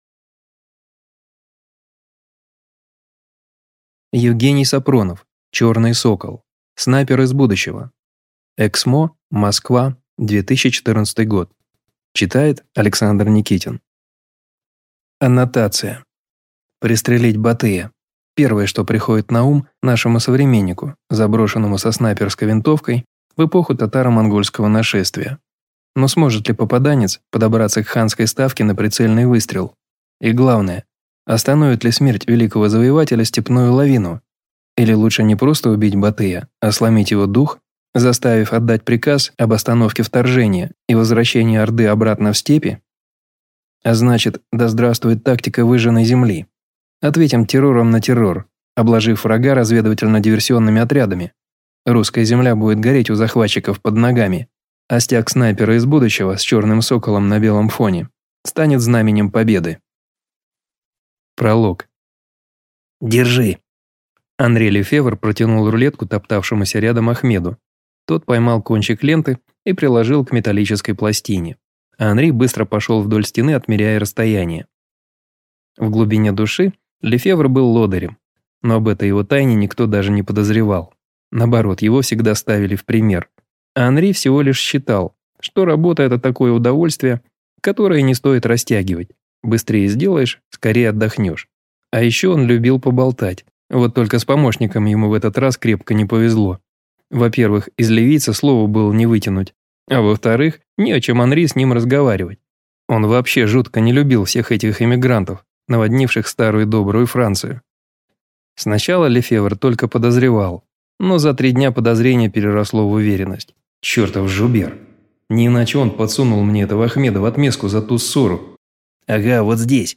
Аудиокнига Черный сокол. Снайпер из будущего | Библиотека аудиокниг